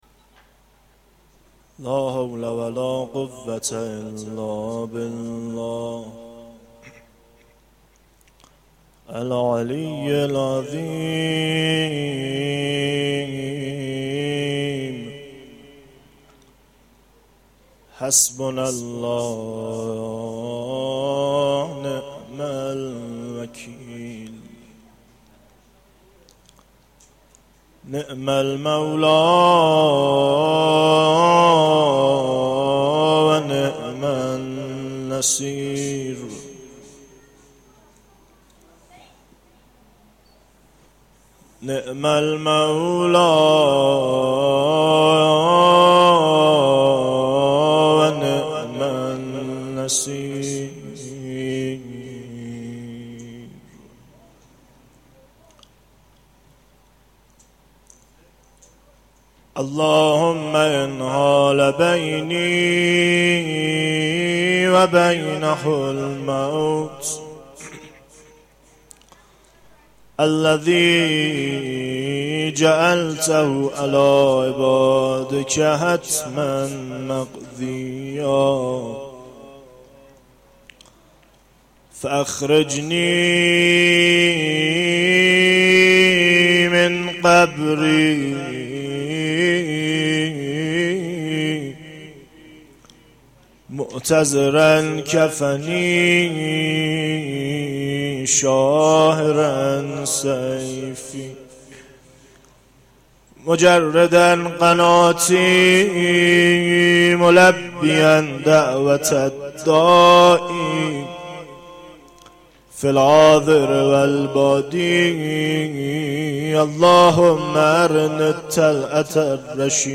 مناجات 6
مناجات-5.mp3